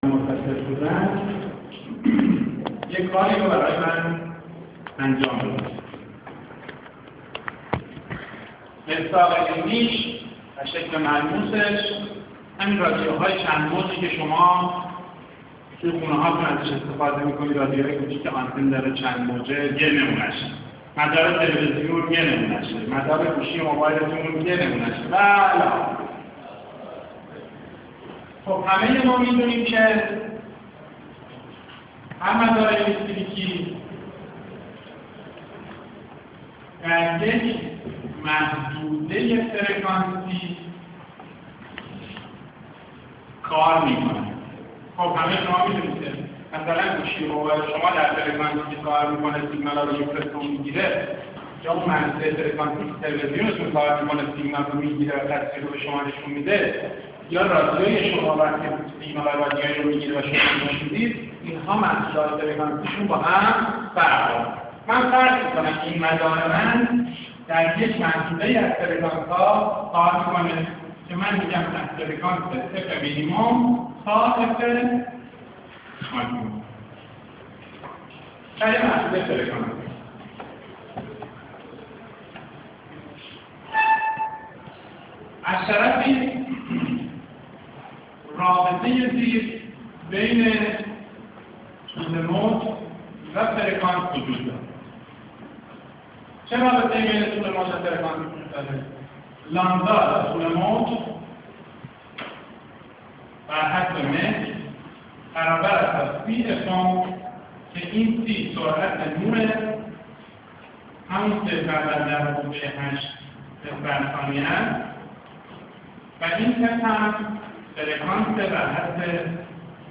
تدریس صوتی درس مدارالکتریکی 1